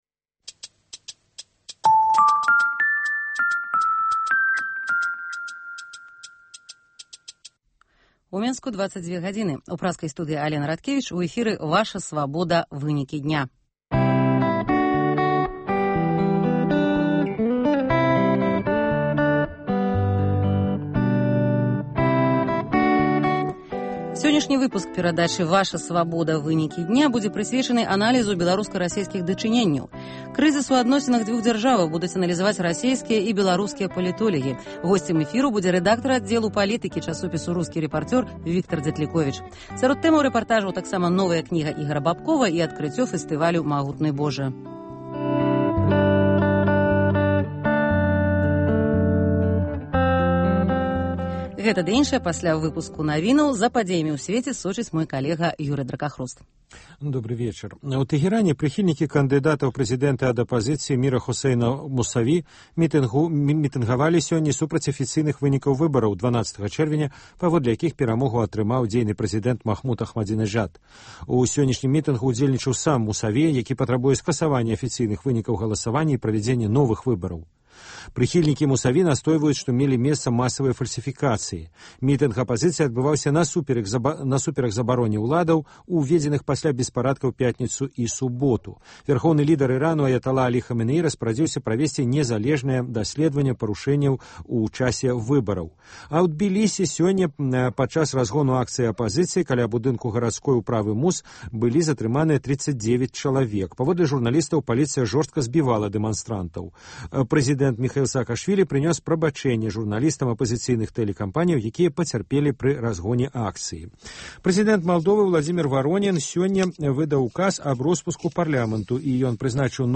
Асноўныя падзеі, бліц-аналіз, досьледы і конкурсы, жывыя гутаркі, камэнтары слухачоў, прагноз надвор'я, "Барды Свабоды".